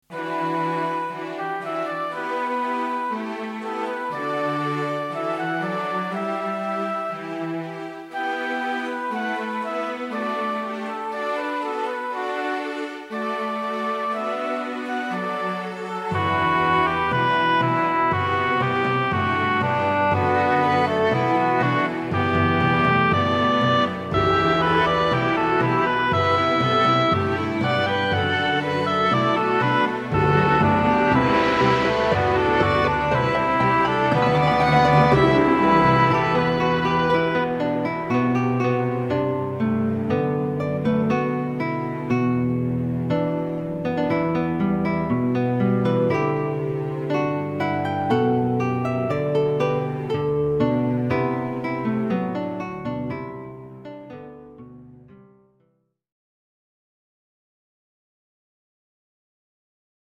klassiek
Wandeling door een oerbos, achtergrondmuziek